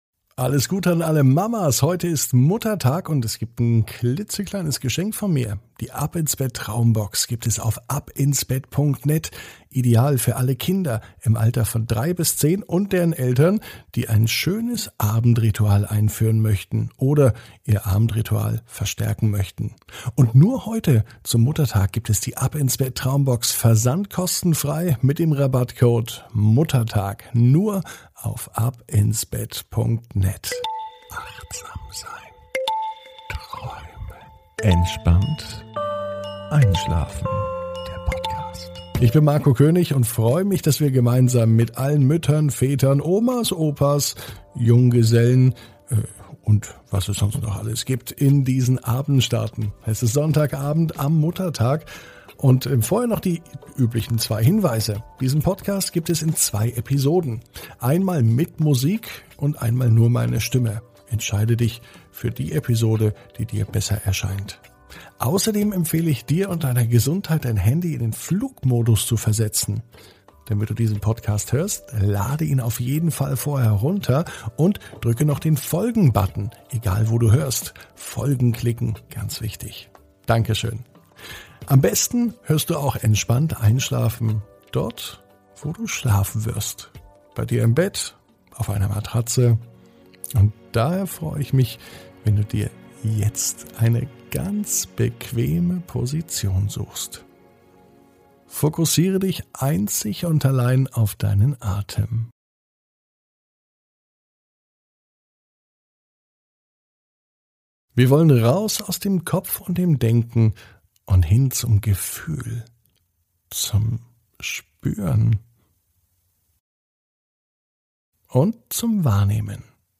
(ohne Musik) Entspannt einschlafen am Sonntag, 09.05.21 ~ Entspannt einschlafen - Meditation & Achtsamkeit für die Nacht Podcast